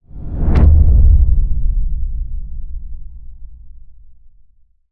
cinematic_deep_low_whoosh_impact_02.wav